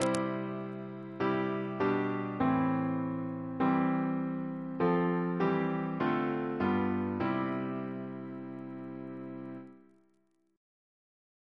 Single chant in C Composer: Jonathan Battishill (1738-1801) Reference psalters: ACP: 246; OCB: 189